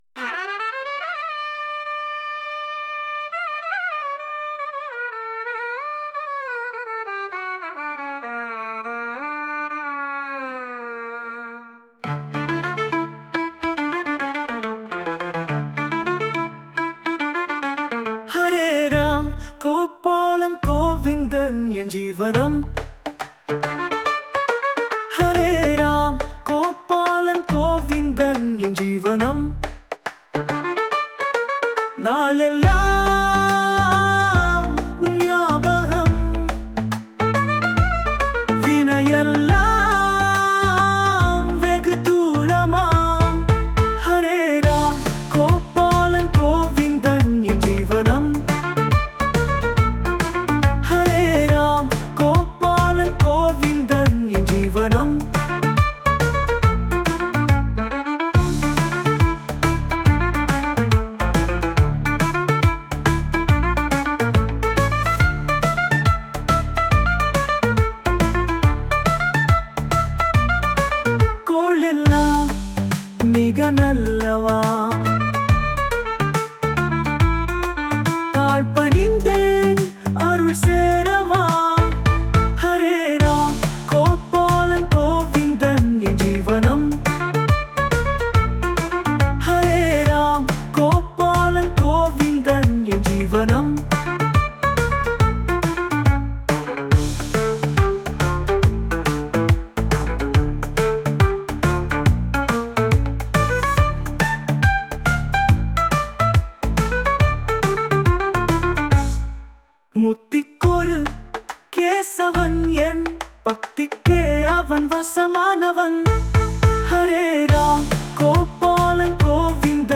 Posted in With AI Audio, தமிழ் அபங்கங்கள், பாடல்கள்